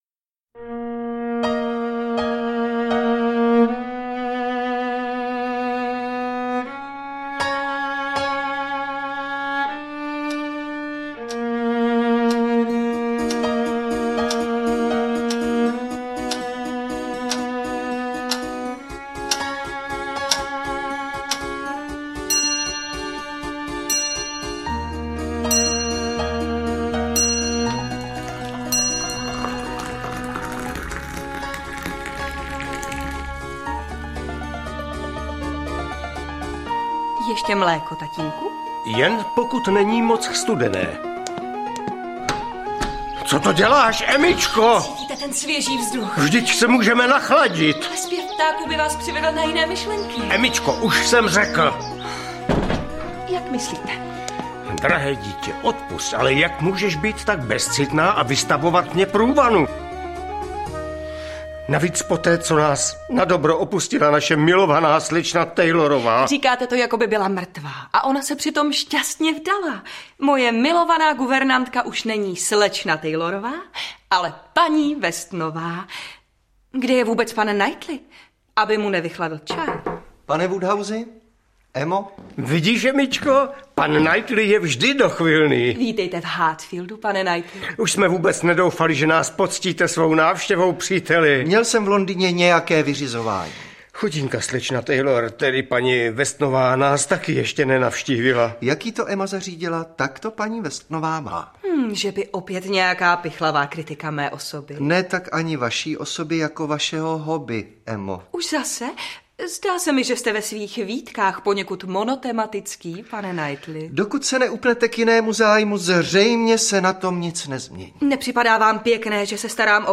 Emma audiokniha
Ukázka z knihy